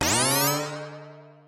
raygun.mp3